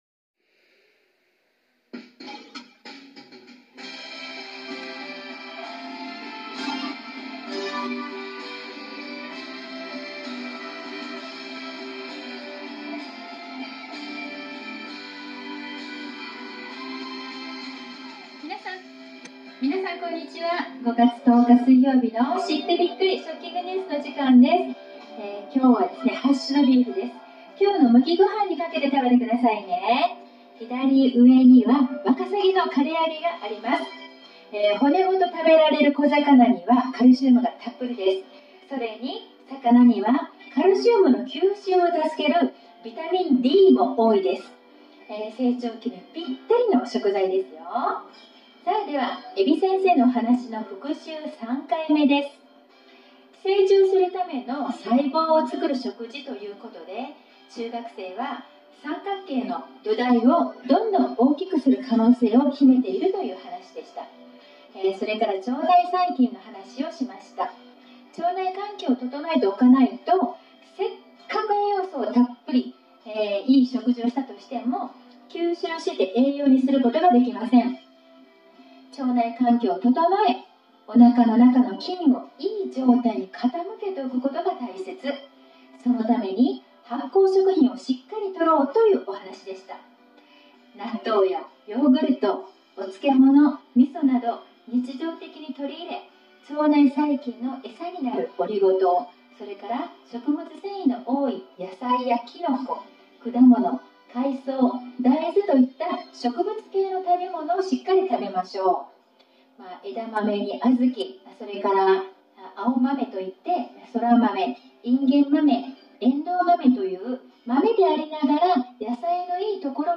→食育放送５月１０日分(今日の録音はうまくいってなくてお聞き苦しいと思います。